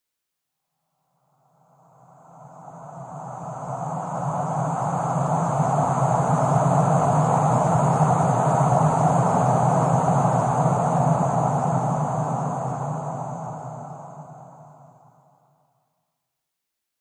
描述：垫子的声音，有较高的音调，几乎是风笛的声音，
Tag: flstudio 爱迪生 FL 环境 声景 黑暗 哀乐